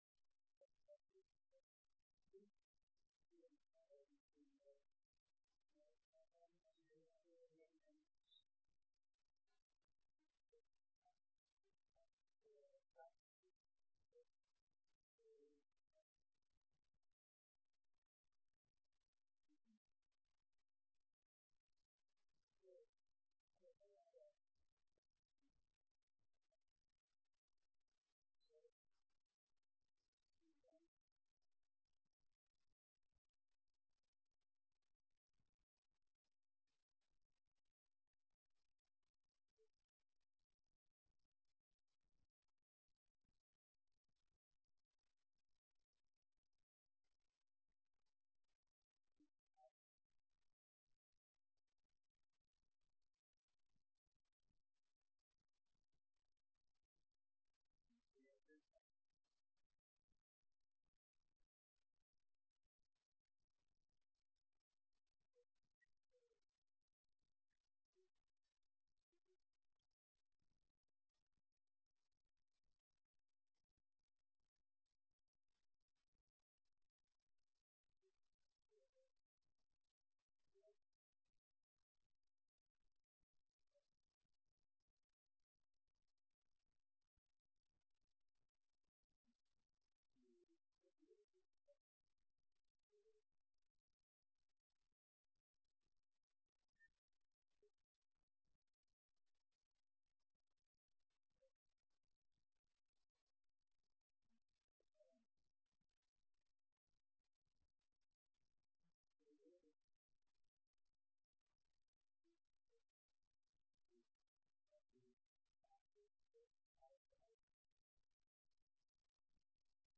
Oct 29, 2007 Public hearing Notice